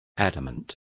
Complete with pronunciation of the translation of adamant.